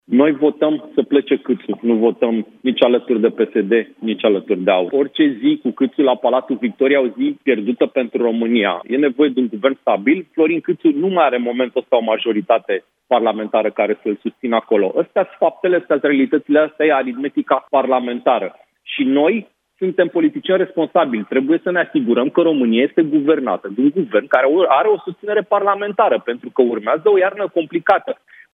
Prioritatea este să îl înlăture pe Florin Cîțu de la Palatul Victoria a spus deputatul la Europa FM: